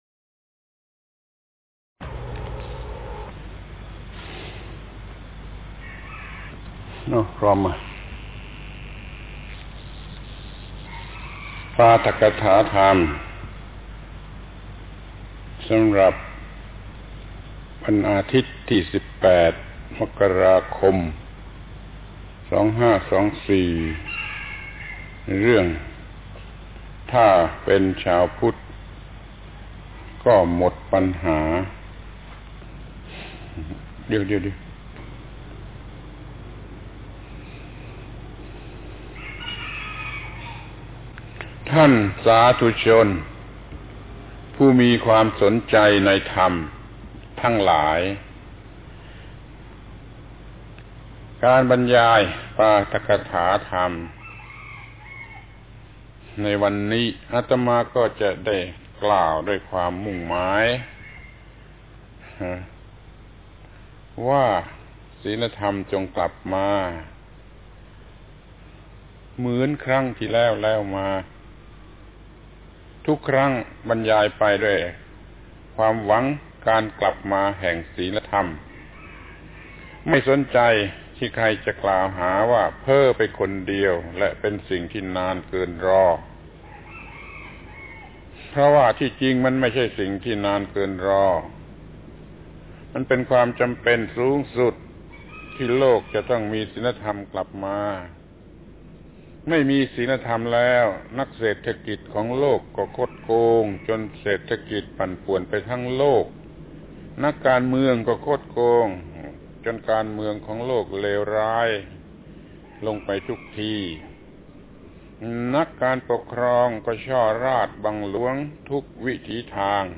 ปาฐกถาธรรมทางวิทยุ ชุด.ศีลธรรมกลับมา. 2523 ครั้งที่ 31 ธรรมในฐานะที่ต้องรู้ว่าเป็นชาวพุทธก็หมดปัญหา